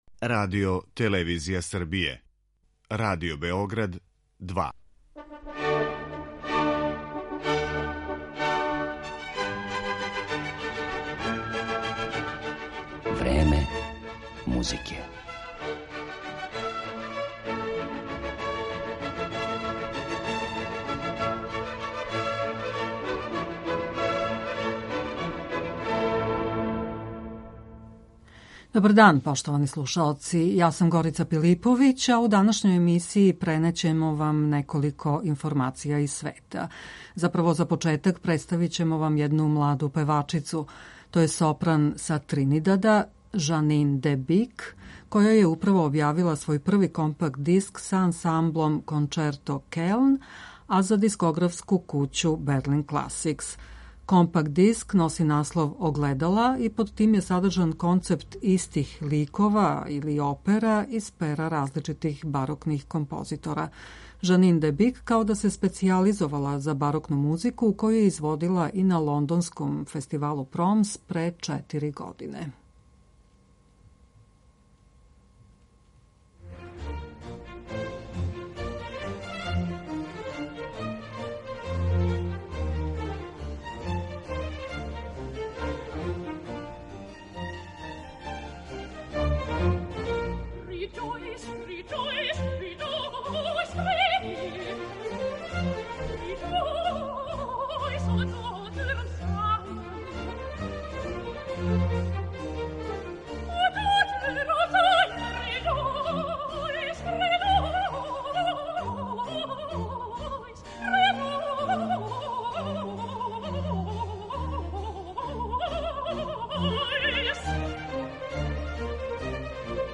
Слушаћете музику Хендла, Шуберта, Шостаковича и других композитора.